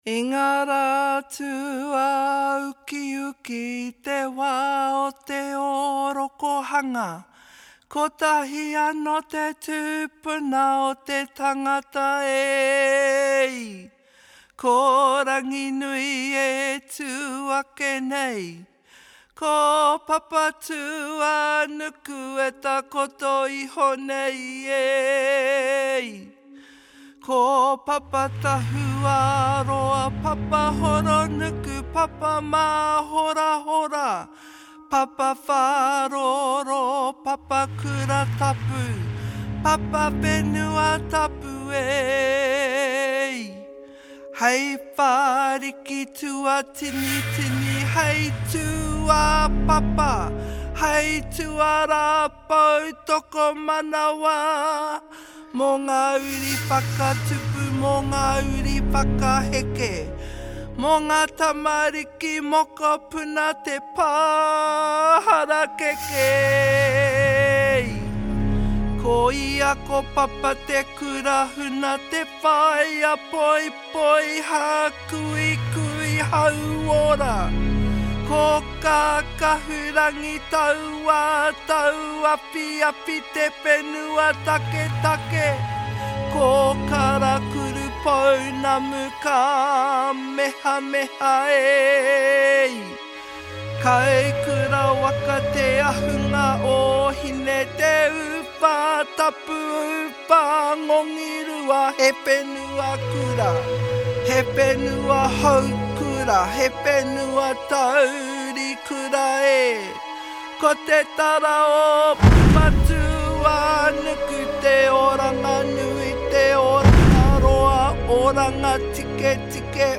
accompaniment